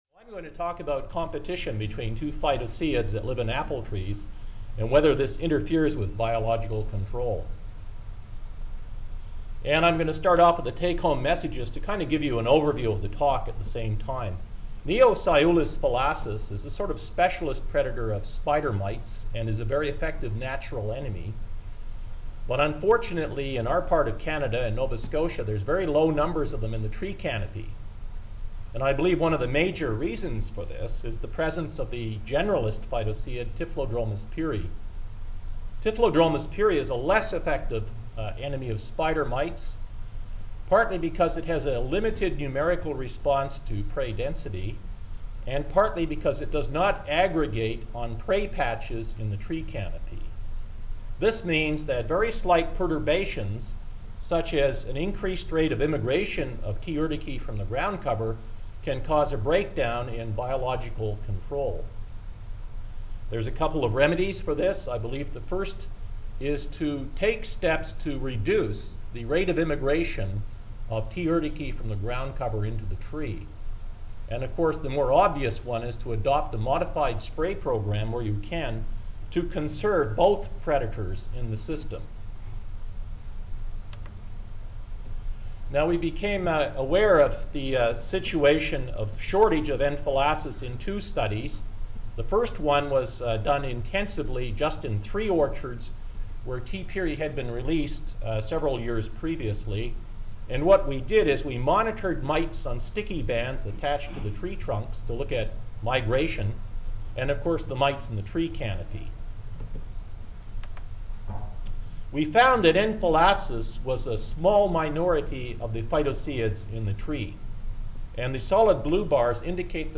8:41 AM Recorded presentation Audio File Presentation 1320 Does competition between Typhlodromus pyri and Neoseiulus fallacis impede impede biological control of Tetranychus urticae in apple orchards?